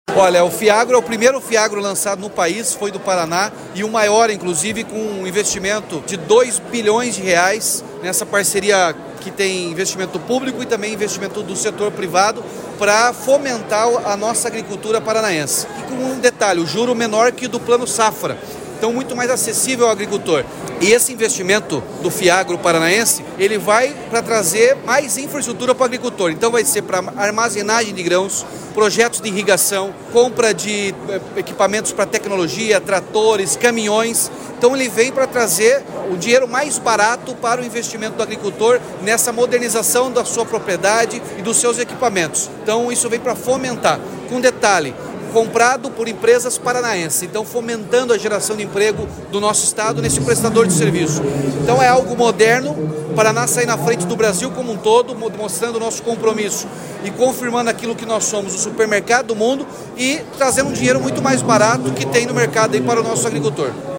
Sonora do governador Ratinho Junior sobre o Fundo de Investimento nas Cadeias Produtivas do Agro